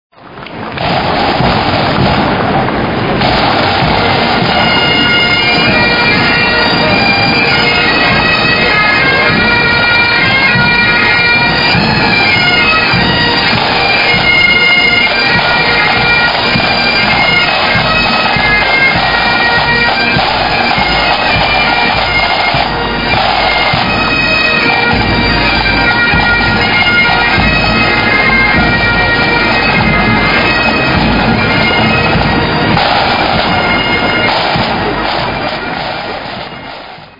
I suoni delle cornamuse (Mp3 92kbytes)
Quello che mi stupisce è che sono più le "Pipes Band", con costumi scozzesi, cornamuse originali e musiche originali, delle Bande tradizionali.
pipes.mp3